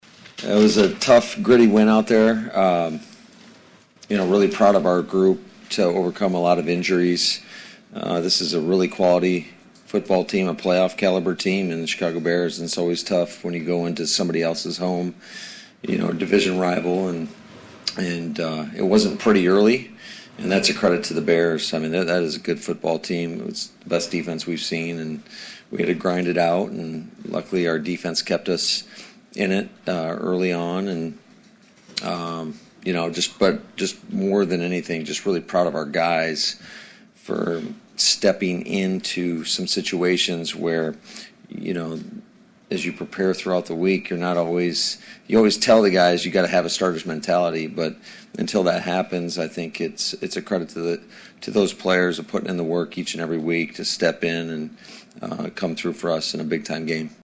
Matt LaFleur and microphone at Solider Field.